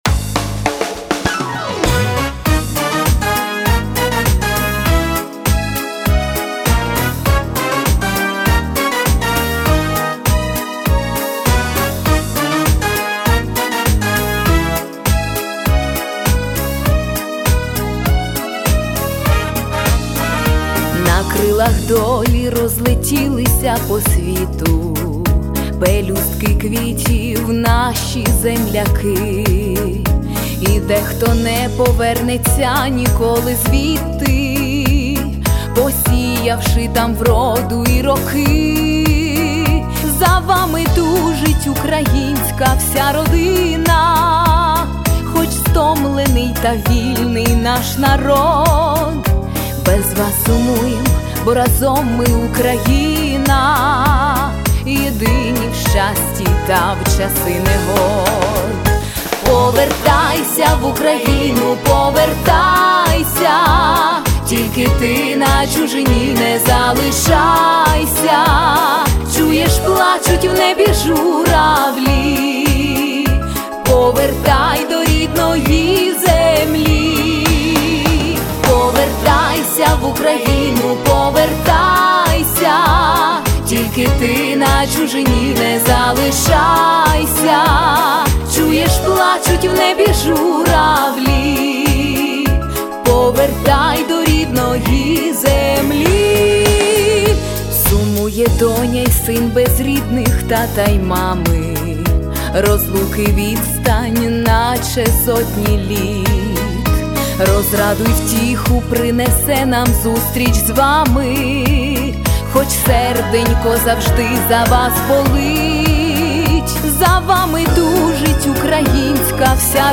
гарно, актуально! give_rose чимось нагадало стиль Таїсії Повалій))